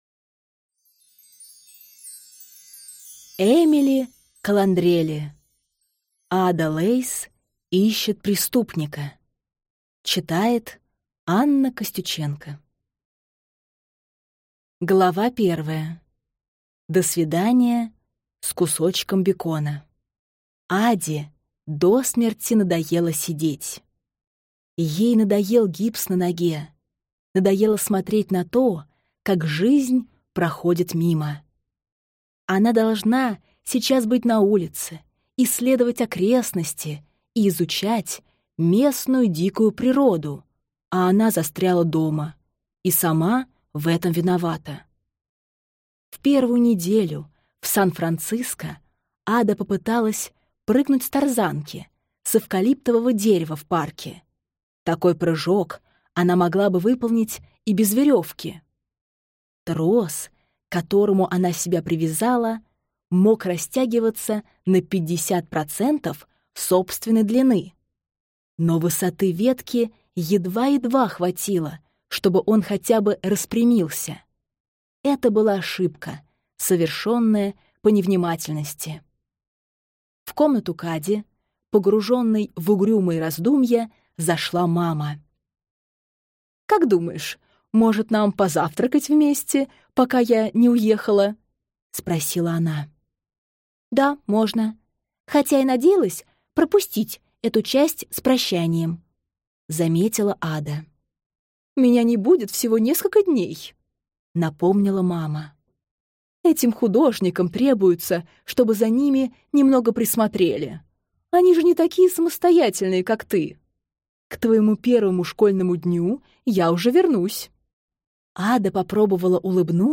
Аудиокнига Ада Лейс ищет преступника | Библиотека аудиокниг